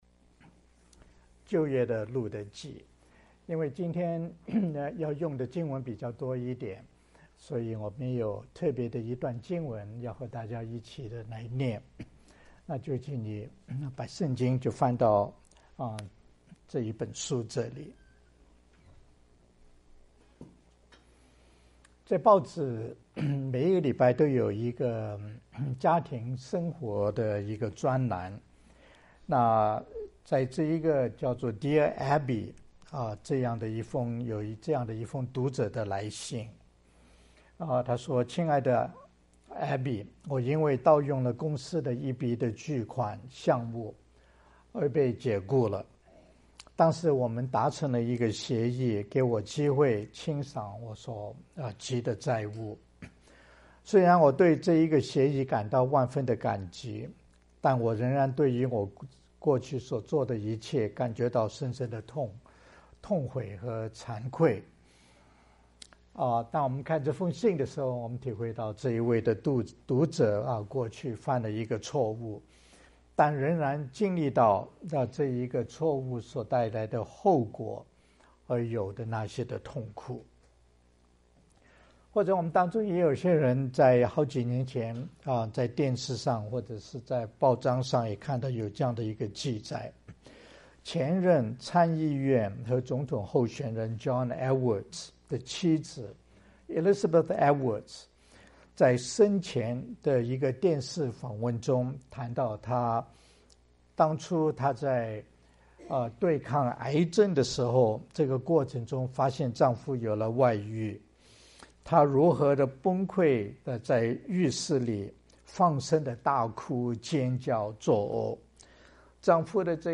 牧師